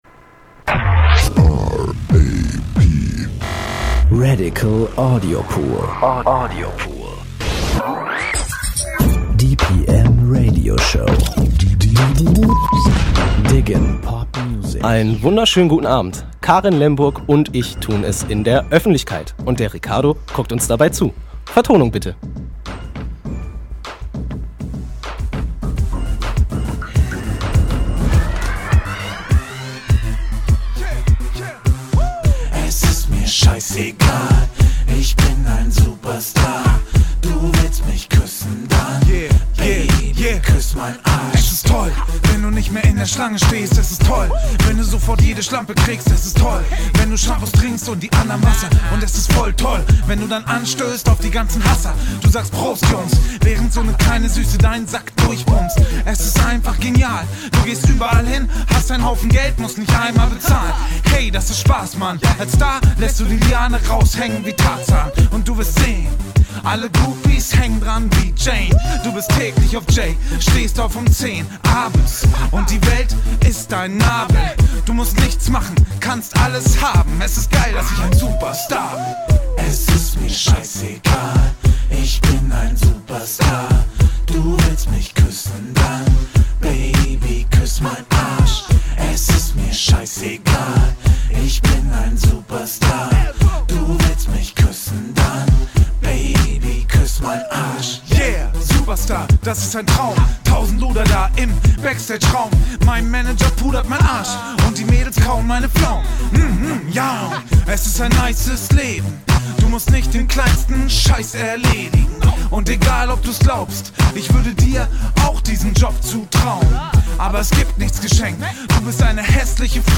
Reportage über das Klavierstimmen